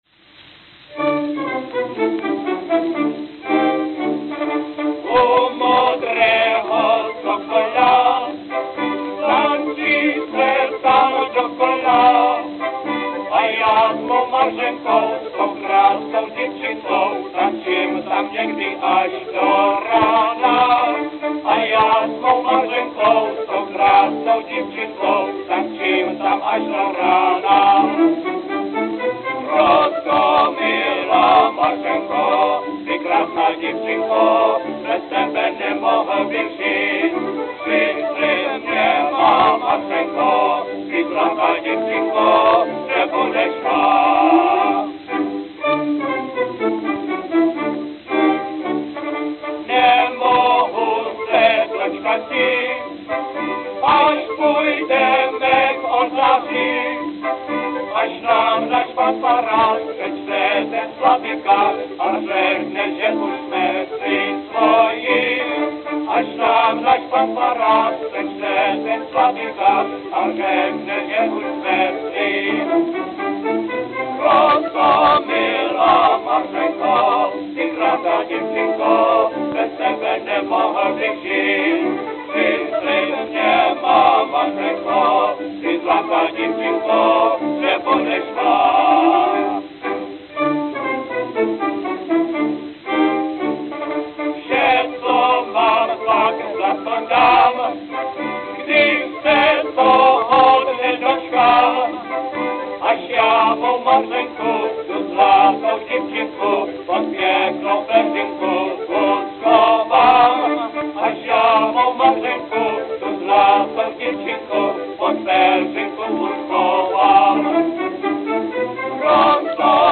New York, New York New York, New York
Note: Worn at start. Groove wear at loud passages.